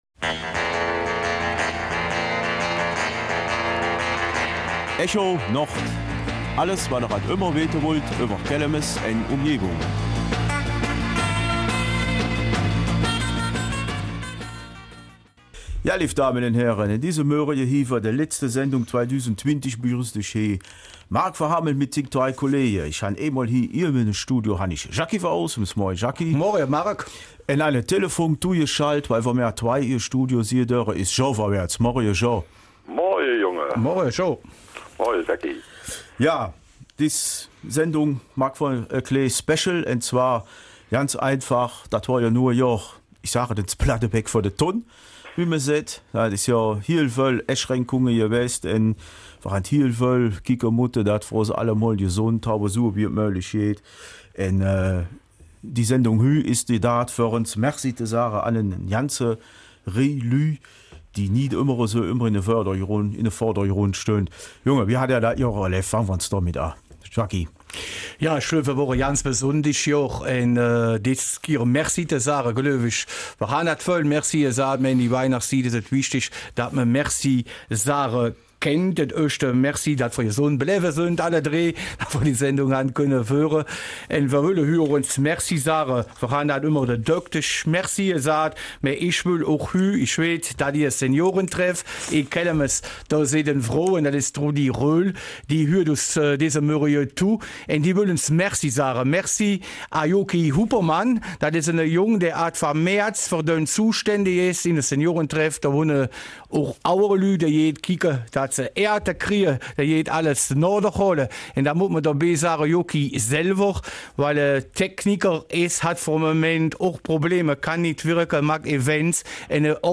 Kelmiser Mundart